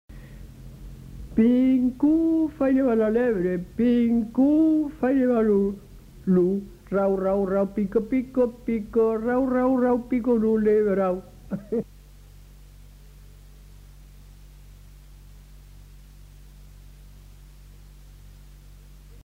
Aire culturelle : Haut-Agenais
Genre : chant
Effectif : 1
Type de voix : voix d'homme
Production du son : chanté
Danse : polka piquée